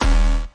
Buzz Error (2).wav